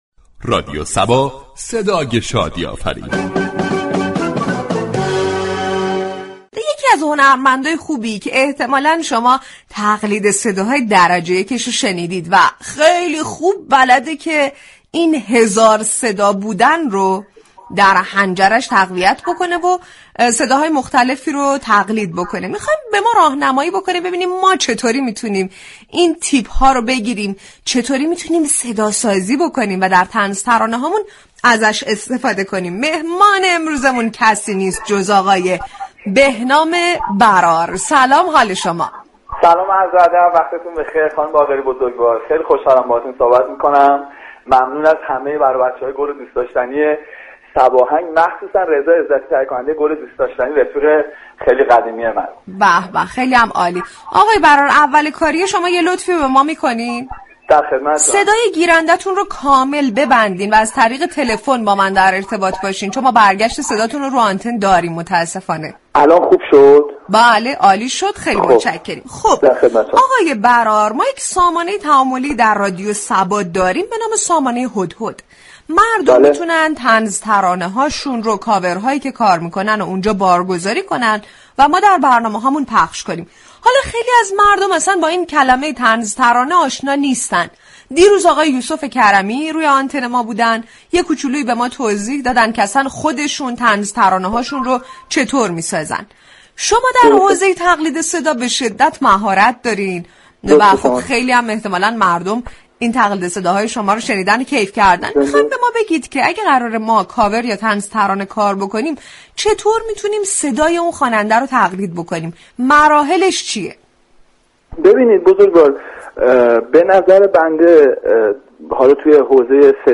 وی در این برنامه با تقلید صدای بازیگران سریال پایتخت لبخند بر لبان مخاطبان ایجاد كرد و در ادامه تمرین و استمرار در آن را لازمه رسیدن به موفقیت در تقلید خوب یك صدا دانست .